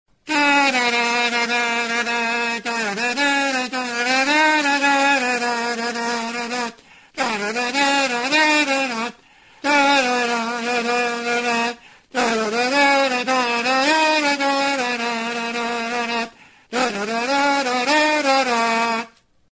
Music instrumentsTURUTA-ORRAZIA
Membranophones -> Mirliton
Recorded with this music instrument.
Plastiko txuriz egindako orrazia da.